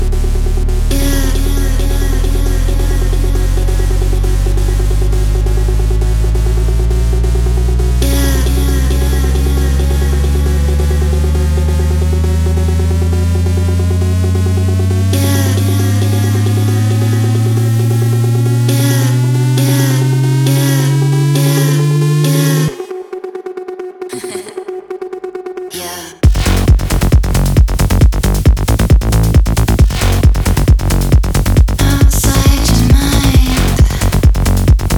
Techno Dance
Жанр: Танцевальные / Техно